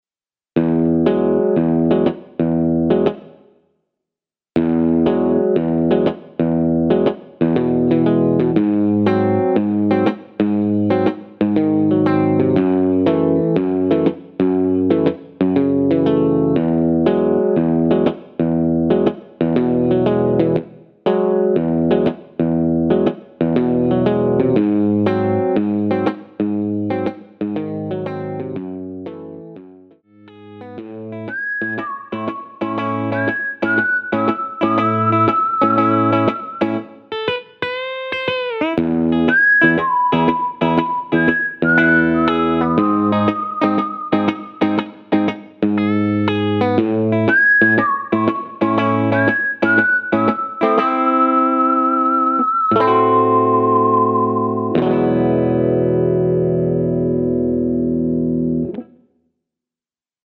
전주가 없는 곡이라 전주 1마디 만들어 놓았습니다 (미리듣기 참조)
앞부분30초, 뒷부분30초씩 편집해서 올려 드리고 있습니다.
중간에 음이 끈어지고 다시 나오는 이유는